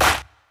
07_Clap_17_SP.wav